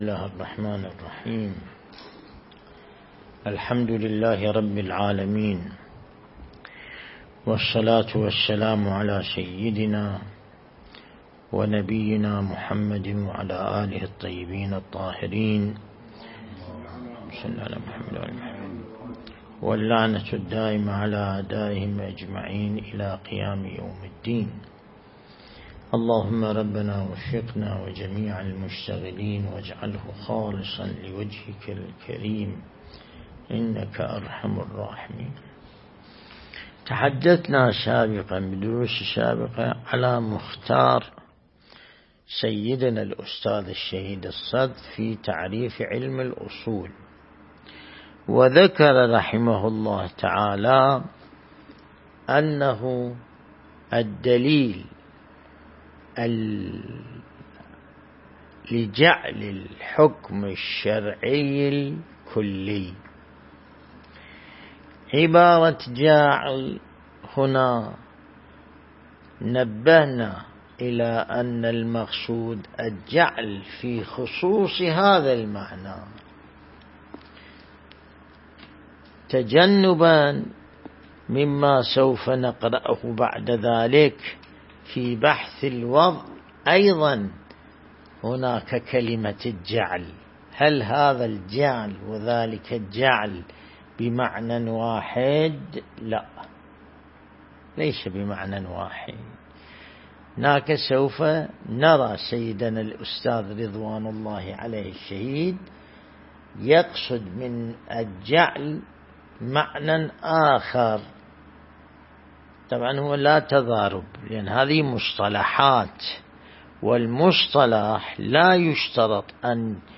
درس البحث الخارج الأصول (20)
النجف الأشرف